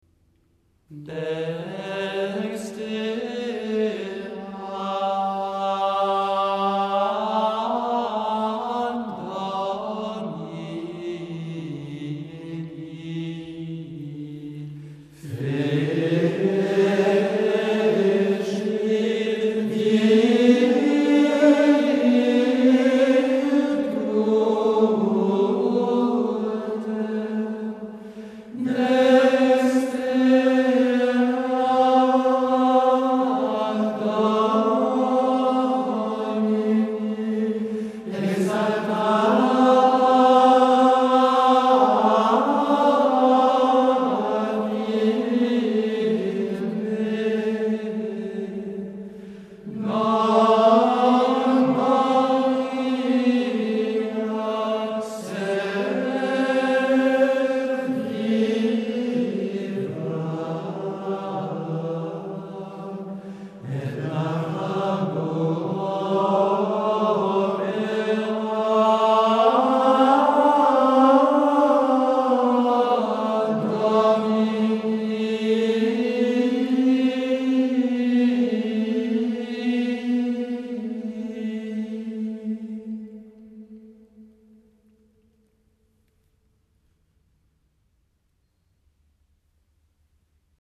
• randol journées liturgiques grégorien offertoire
Ici, ce n’est donc pas la main mais le bras, cela indique davantage la puissance que la tendresse et cela se fait sentir dans la facture de la pièce qui est extrêmement vigoureuse.
Du point de vue de l’interprétation, même si la pièce est marquée en 2 mode, ce chant d’offertoire nécessite du souffle et une grande chaleur vocale.
Donc une atmosphère très ferme, très grande, solennelle.
Une première phrase forte d’un bout à l’autre.
La deuxième phrase est encore plus forte.
Tout se joue entre le La et le Do, sur les sommets.